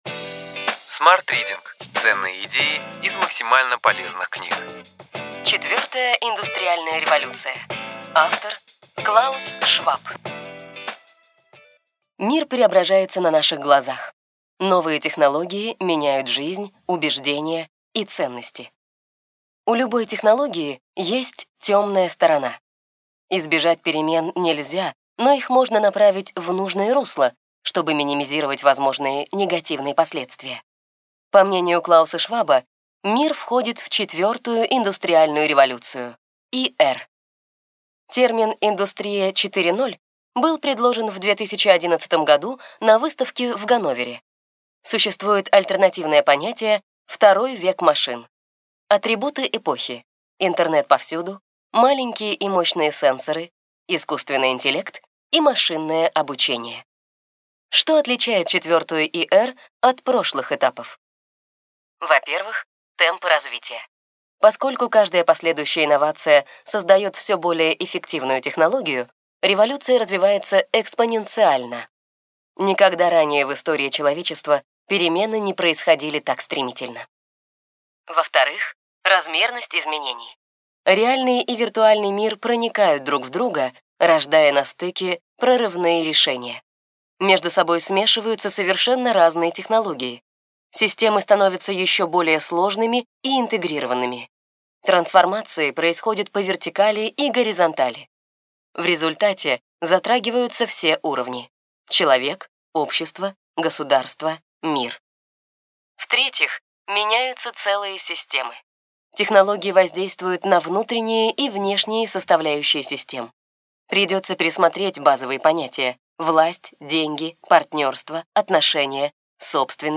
Аудиокнига Ключевые идеи книги: Четвертая индустриальная революция.